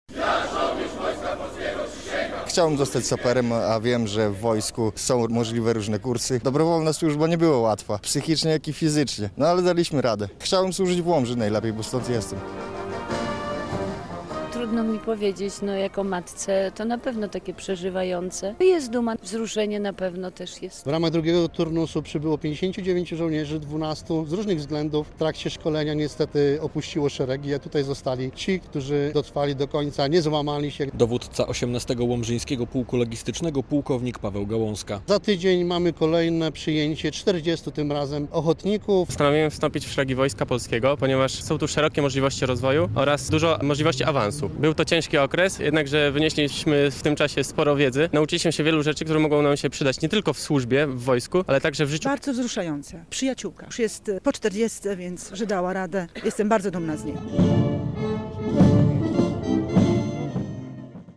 Podczas uroczystości na Starym Rynku żołnierze ślubowali bronić swojej ojczyzny.